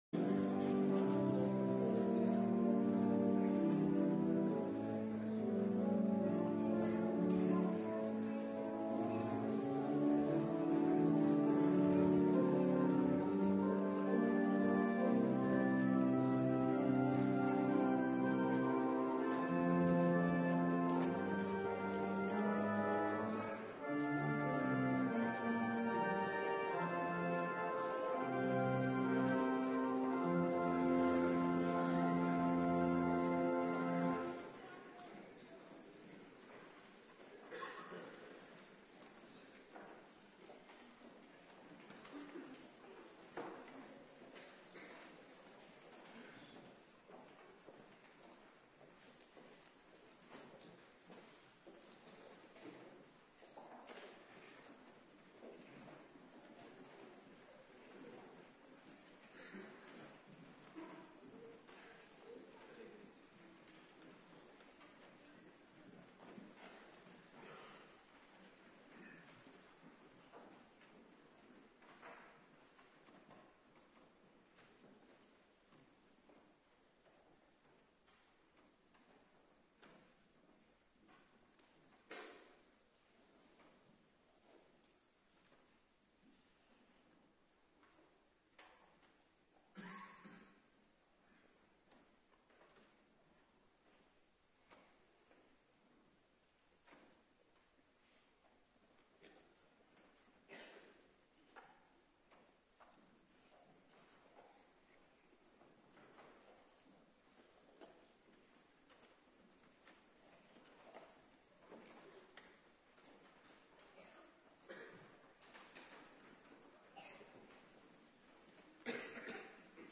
Morgendienst
09:30 t/m 11:00 Locatie: Hervormde Gemeente Waarder Agenda: Kerkdiensten Terugluisteren Doopdienst Ex. 2:23